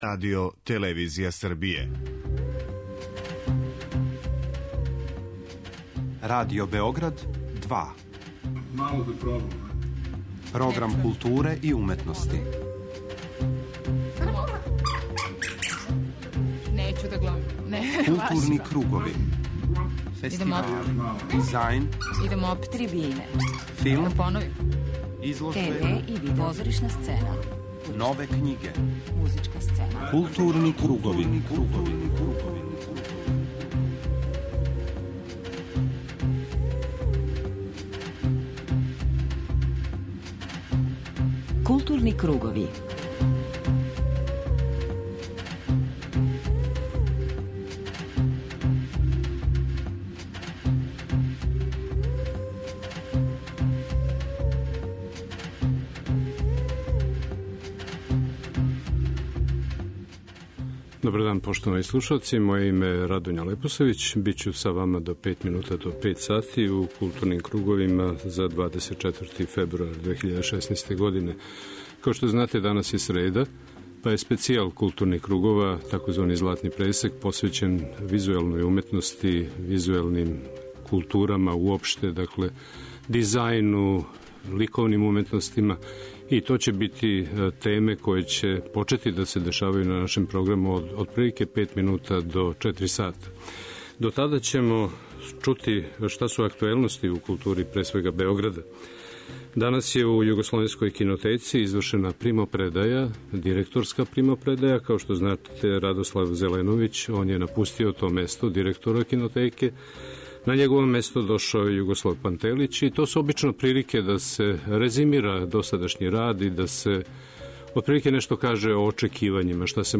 преузми : 41.05 MB Културни кругови Autor: Група аутора Централна културно-уметничка емисија Радио Београда 2.